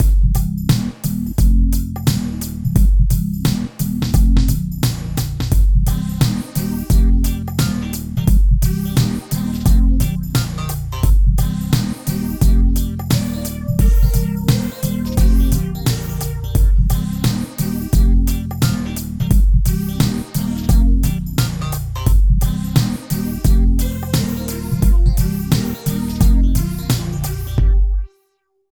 27 LOOP   -R.wav